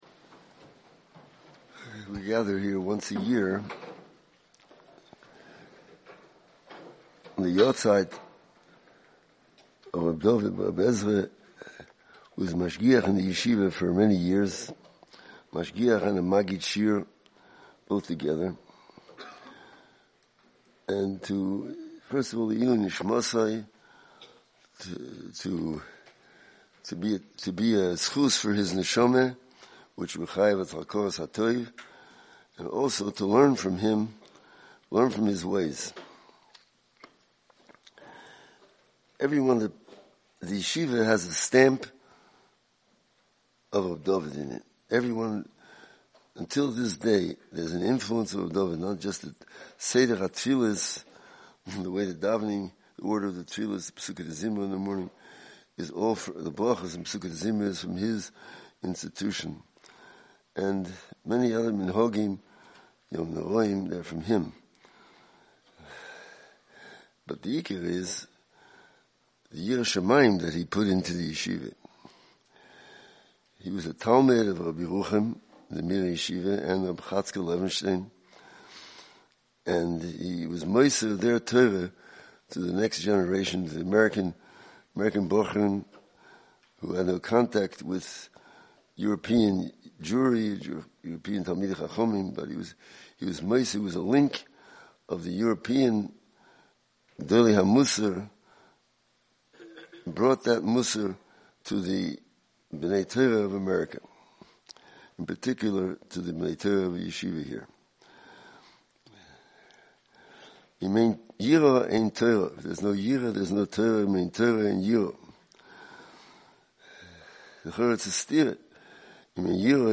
Liluy nishmaso, the Yeshiva held a special program in the Yeshiva Bais Hamedrash, on Thursday evening before the fast.